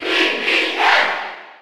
Alph_Cheer_Spanish_PAL_SSB4.ogg.mp3